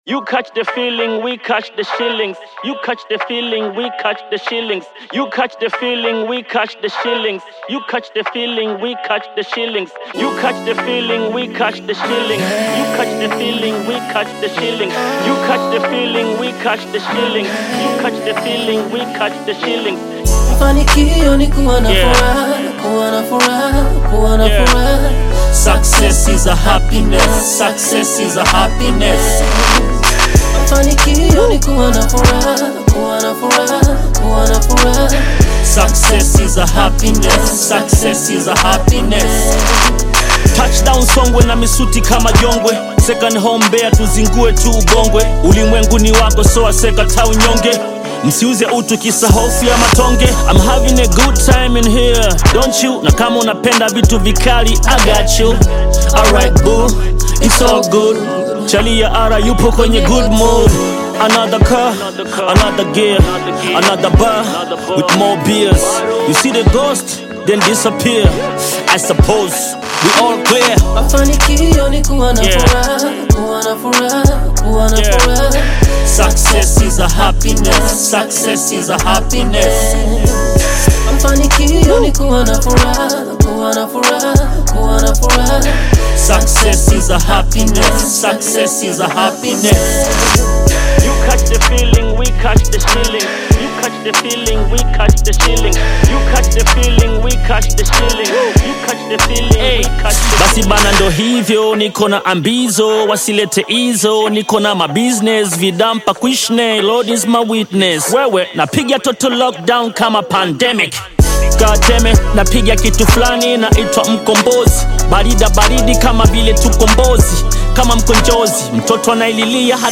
African Music song